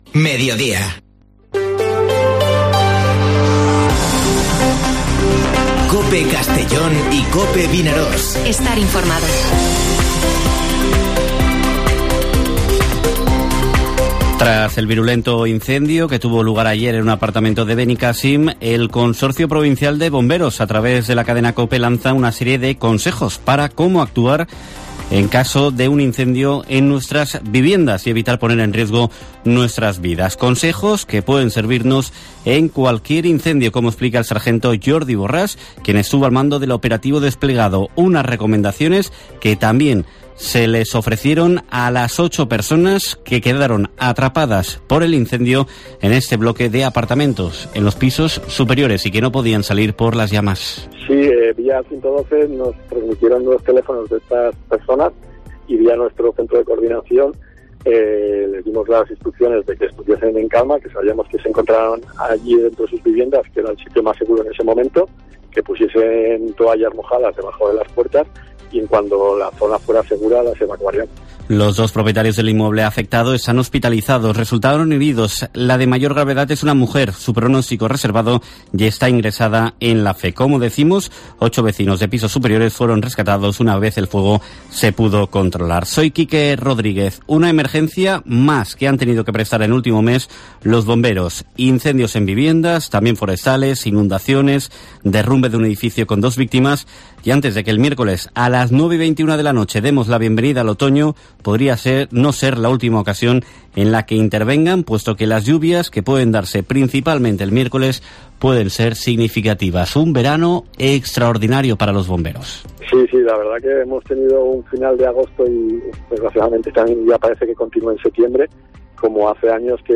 Informativo Mediodía COPE en la provincia de Castellón (20/09/2021)